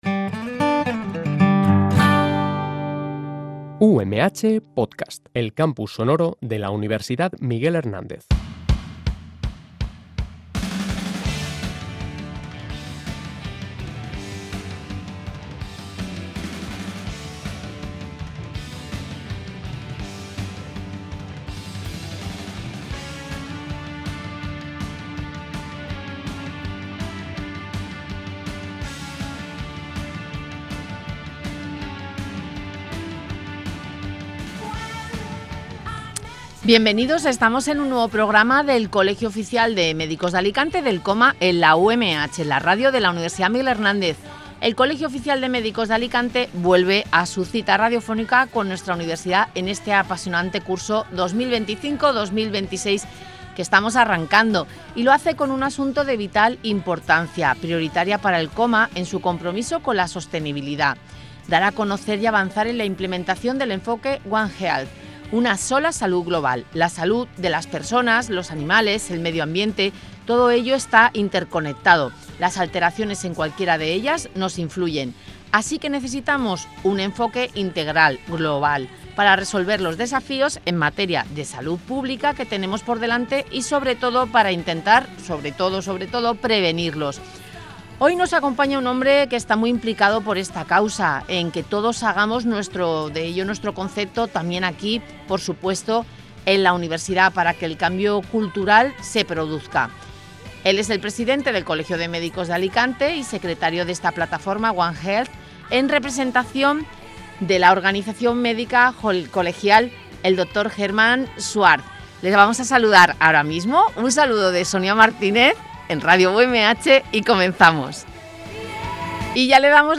El Colegio vuelve a las ondas con un asunto de vital importancia, prioritario en nuestro compromiso con la sostenibilidad: dar a conocer y avanzar en la implementación del enfoque One Health.